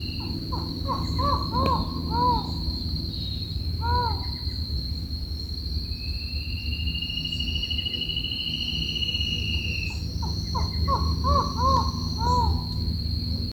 Collared Forest Falcon (Micrastur semitorquatus)
Location or protected area: Parque Provincial Teyú Cuaré
Condition: Wild
Certainty: Observed, Recorded vocal